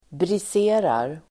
Uttal: [bris'e:rar]